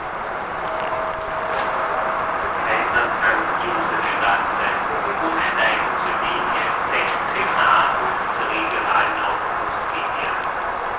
Haltestellenansagen